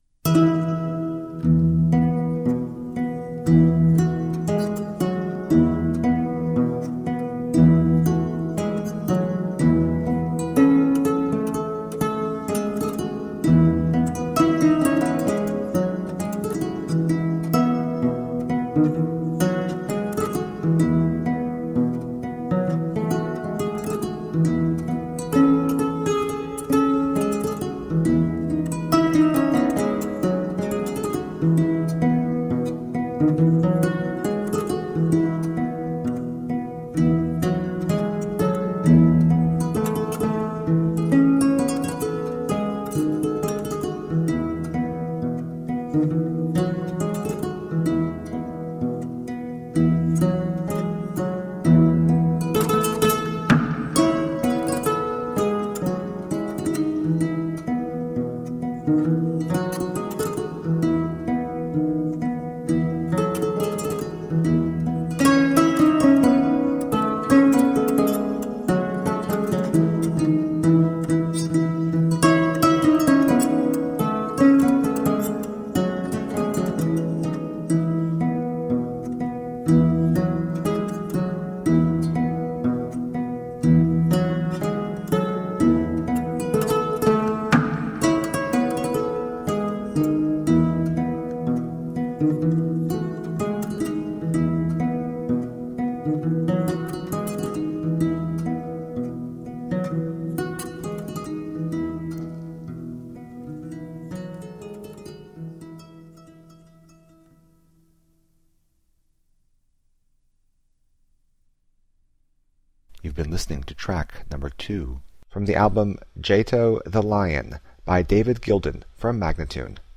Tagged as: New Age, World, African influenced